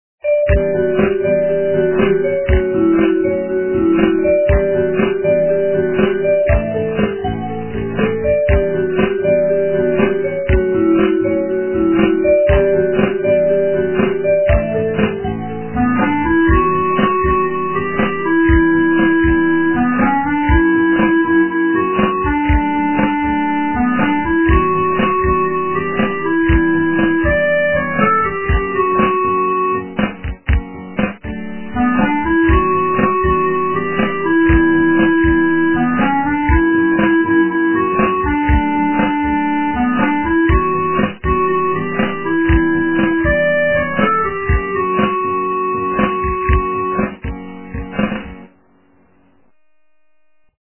- русская эстрада
качество понижено и присутствуют гудки
полифоническую мелодию